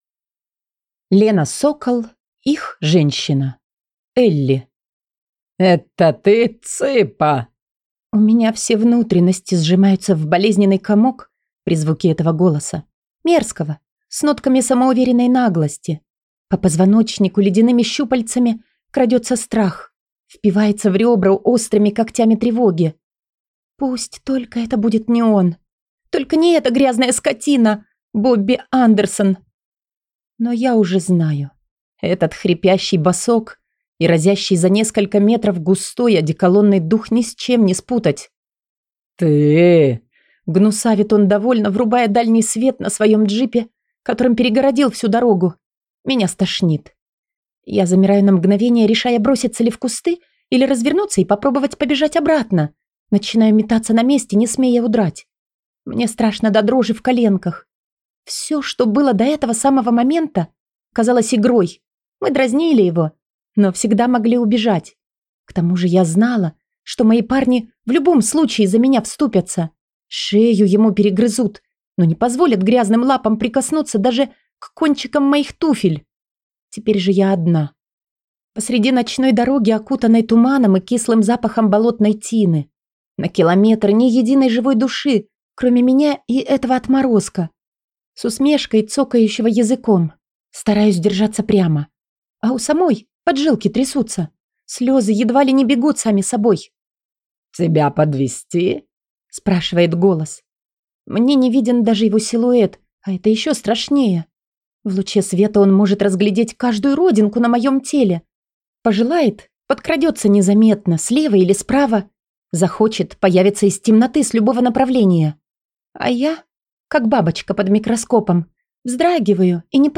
Аудиокнига Их женщина | Библиотека аудиокниг